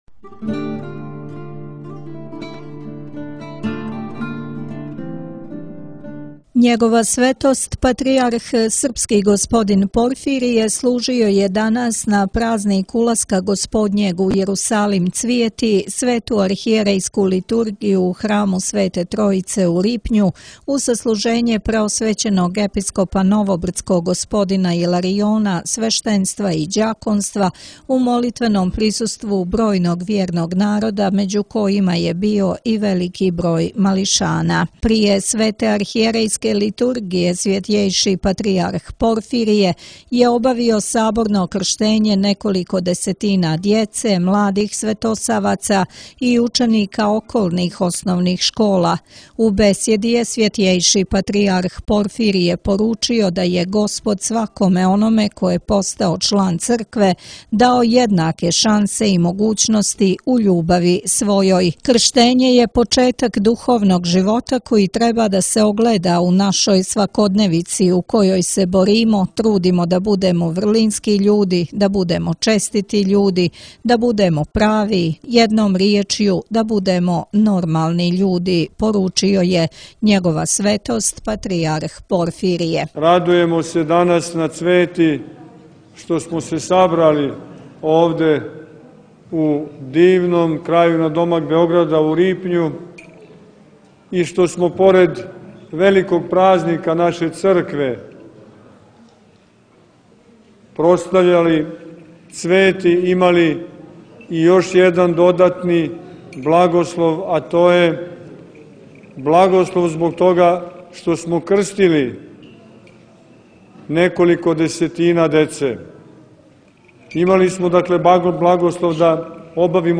crkva Sv. Tekle, Danilovgrad